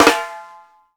FLAM2     -R.wav